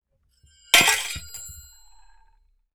Metal_69.wav